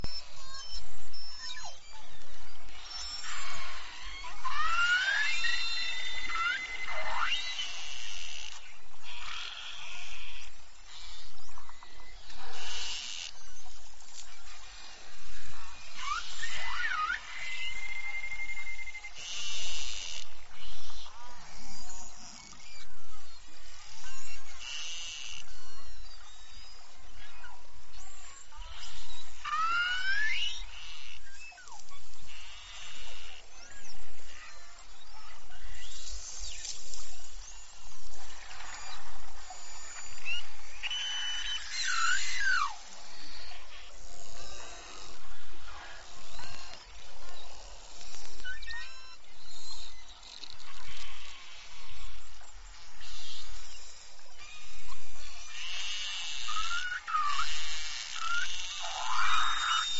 Beluga Son Derece Konuşkandır
Hatta sahip oldukları ses aralıkları ötücü kuşları andırır.
Belugaların coşkulu sesleri sudan, hatta tekne gövdesinden bile duyulabilir. Bunlar ekolokasyon tıklamaları şeklindedir ve çeşitli ıslık, meleme, ötüş, miyavlama ve hatta çan benzeri tonlar da duyulur.
beluga-sesleri.mp3